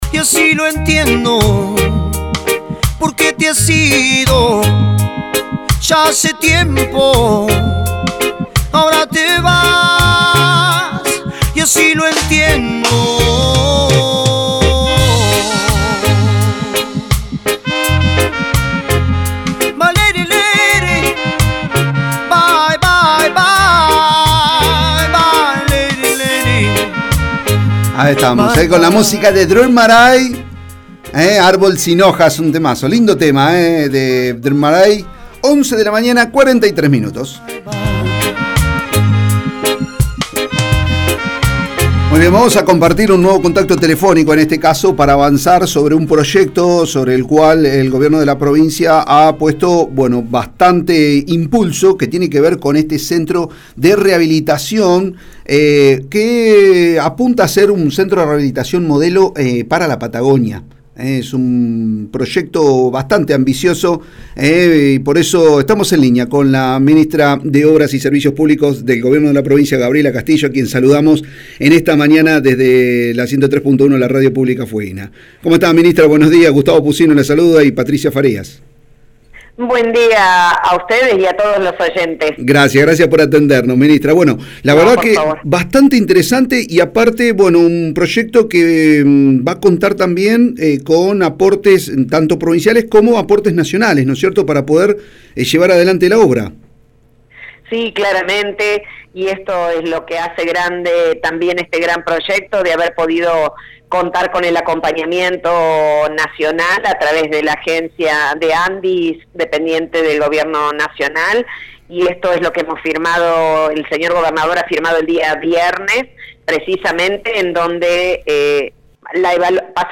En diálogo con el programa “La otra mañana” de la 103.1, la radio pública fueguina, Castillo detallo las diferentes obras que se irán concretando en el corto plazo.